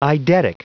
Prononciation du mot eidetic en anglais (fichier audio)
Vous êtes ici : Cours d'anglais > Outils | Audio/Vidéo > Lire un mot à haute voix > Lire le mot eidetic